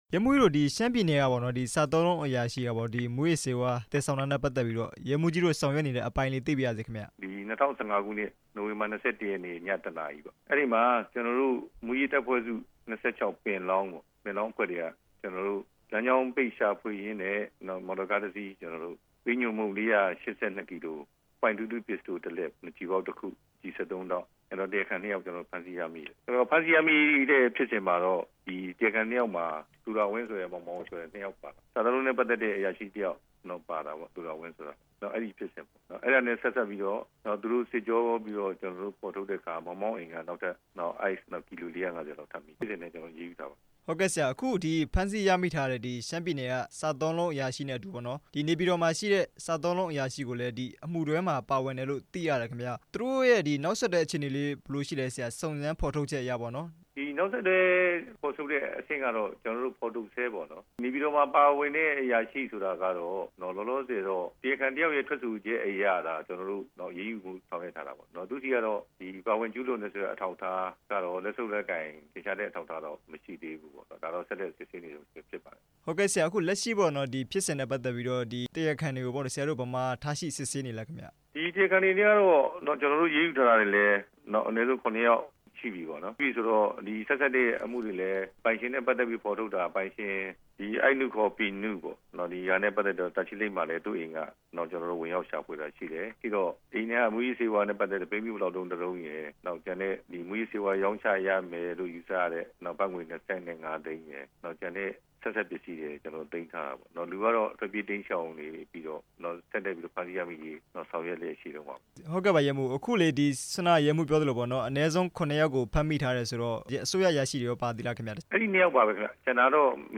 မူးယစ်ဆေးဝါး တားဆီးနှိမ်းနင်းရေး ဌာနမှူး ရဲမှူးကြီး ရွှေညာမောင်နဲ့ မေးမြန်းချက်